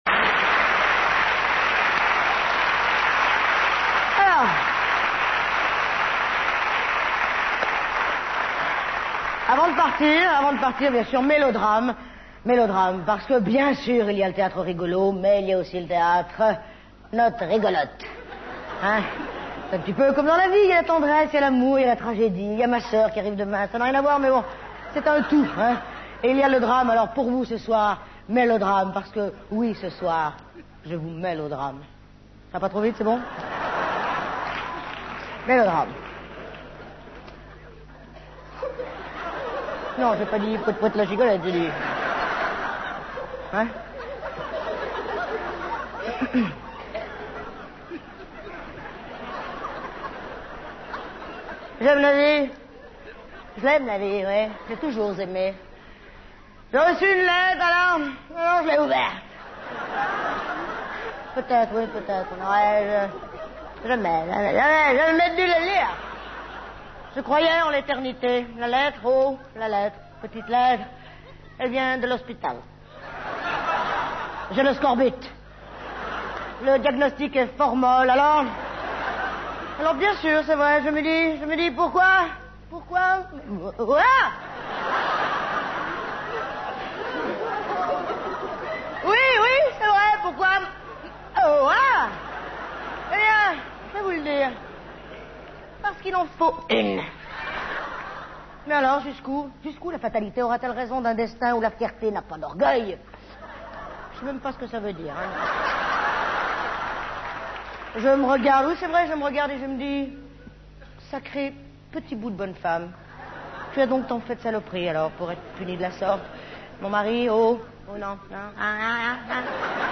Quelques photos !... et des extraits de spectacle.